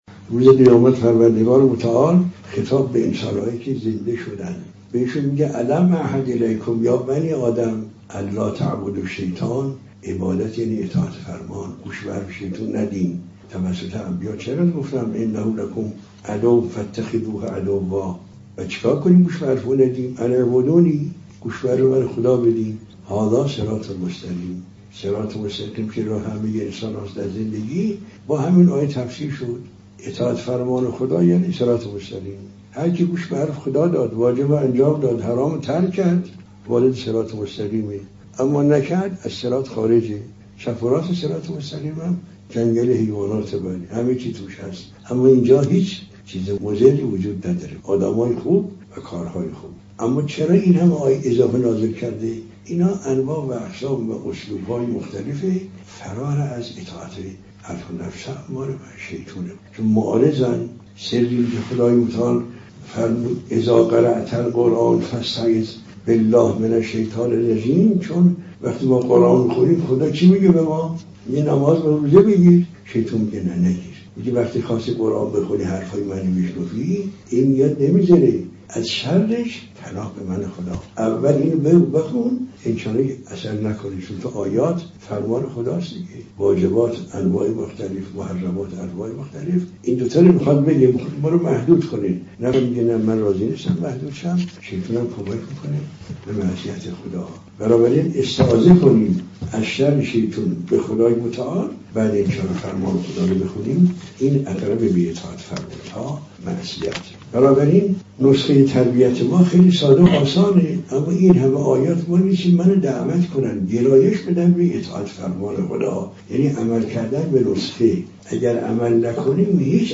دروس اخلاق